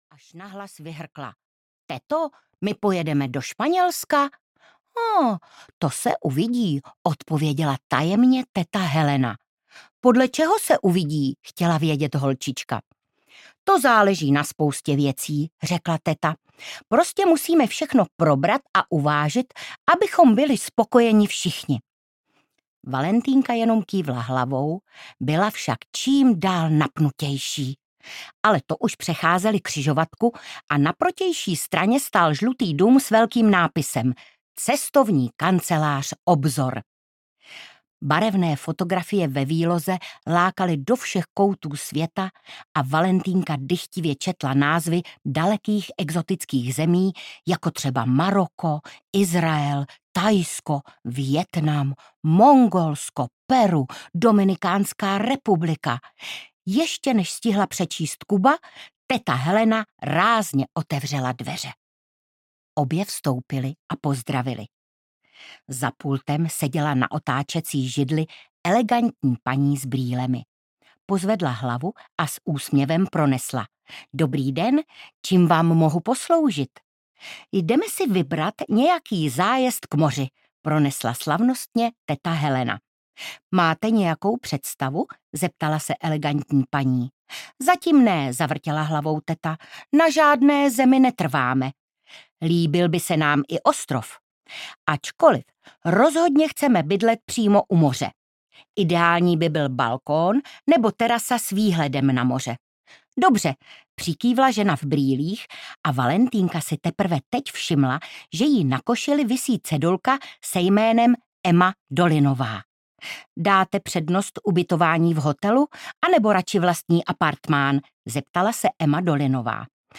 Valentýnka a daleké kraje audiokniha
Ukázka z knihy
• InterpretIvana Andrlová